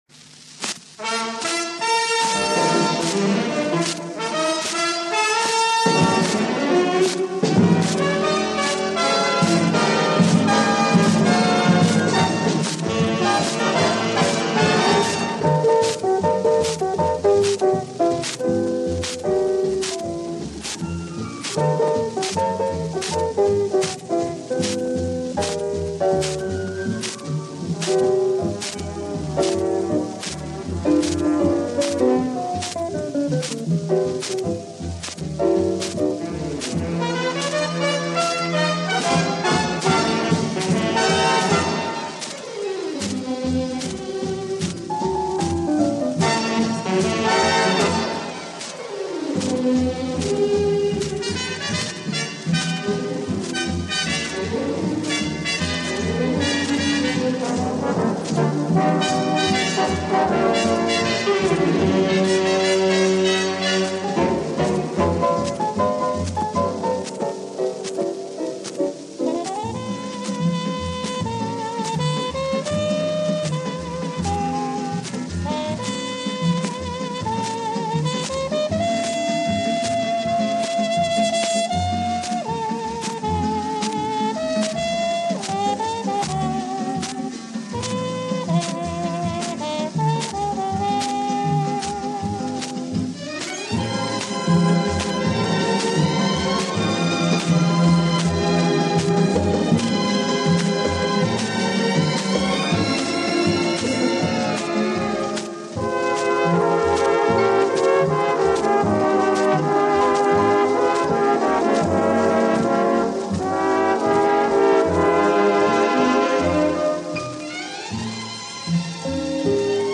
Прошу меня извинить за неважное качество звучания.